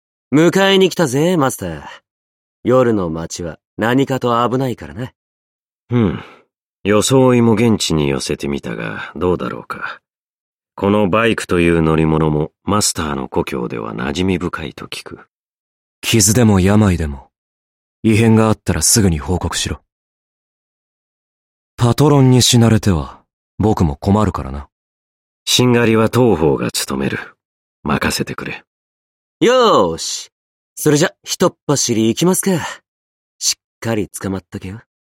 声优 冈本信彦&津田健次郎&铃木崚汰